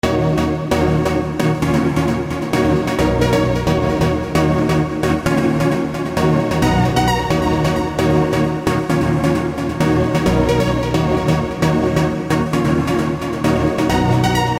恍惚的声音
描述：我在Fruityloops中做了这个循环，简单的合成器循环。
Tag: 132 bpm Trance Loops Synth Loops 2.45 MB wav Key : Unknown